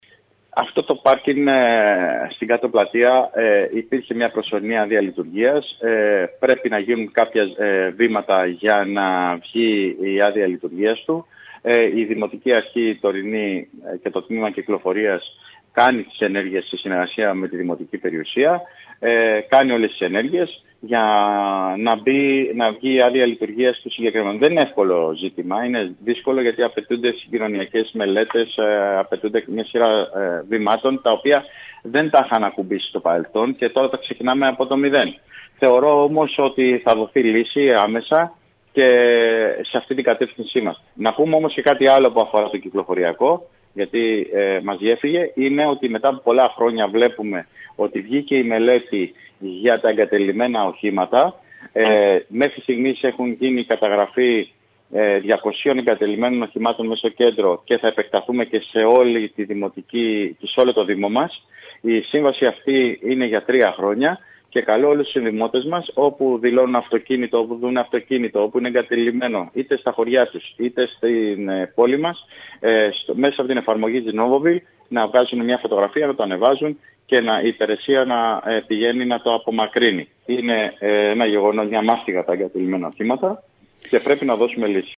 Κανονικά θα λειτουργήσει σε λίγες ημέρες το δημοτικό πάρκινγκ στην κάτω πλατεία. Αυτό ανέφερε μιλώντας στο σταθμό μας ο Αντιδήμαρχος ΤΥ Νίκος Καλόγερος.